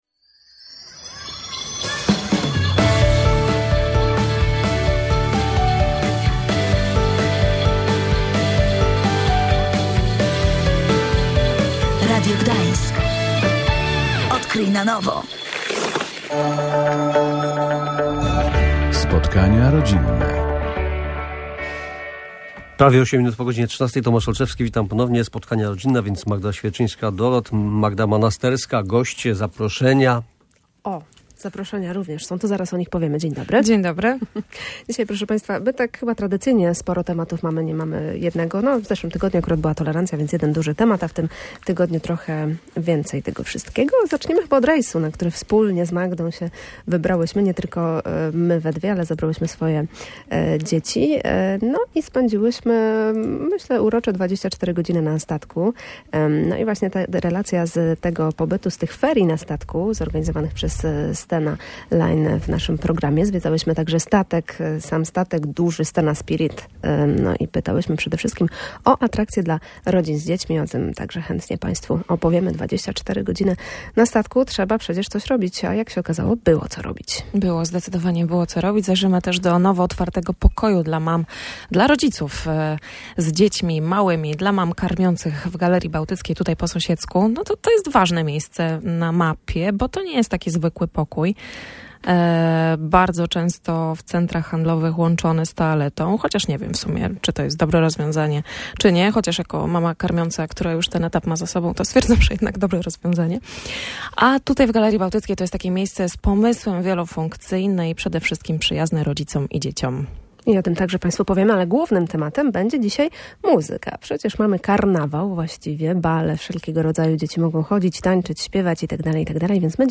Dzieci do 3 roku życia mają wrodzony słuch muzyczny. Potem trzeba tych umiejętności uczyć się na nowo – mówiły w programie Spotkania rodzinne ekspertki. To podobnie jak z pływaniem niemowląt.